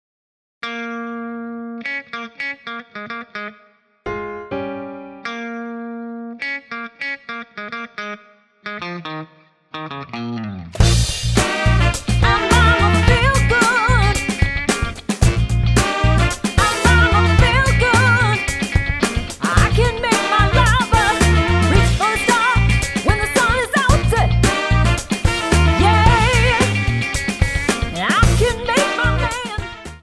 --> MP3 Demo abspielen...
Tonart:Bb Multifile (kein Sofortdownload.